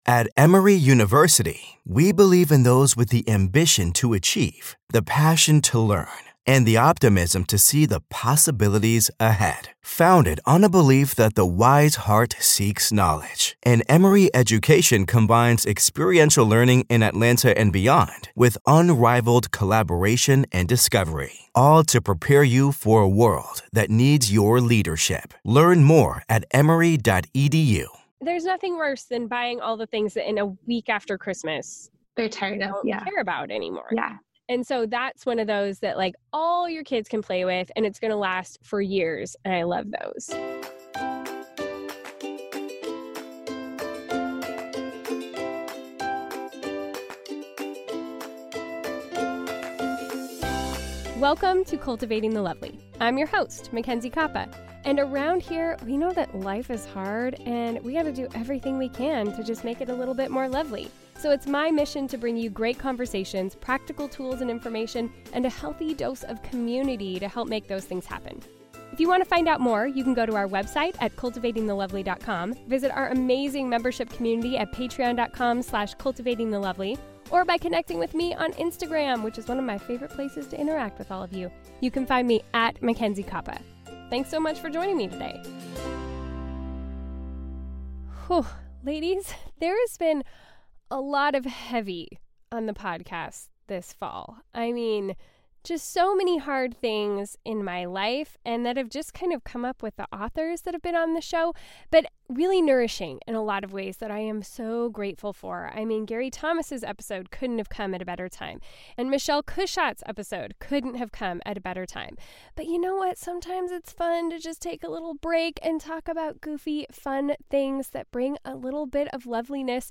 sit down for a fun chat about their favorite Christmas gift ideas for this year